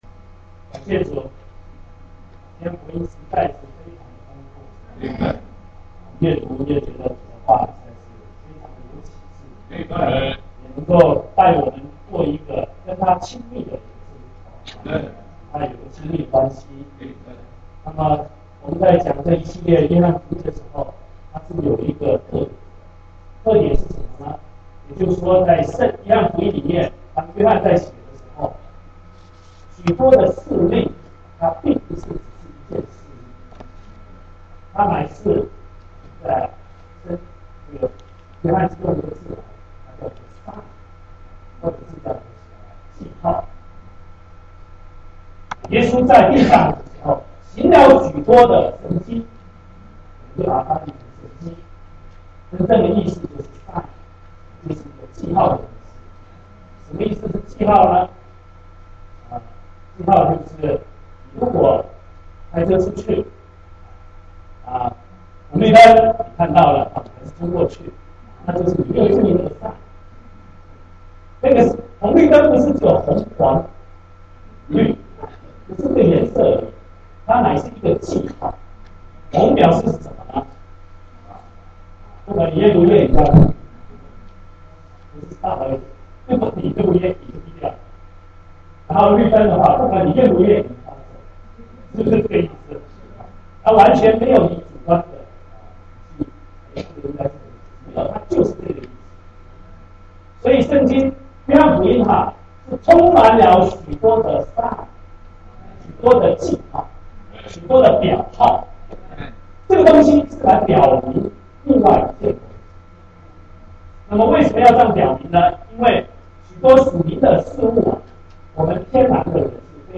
信息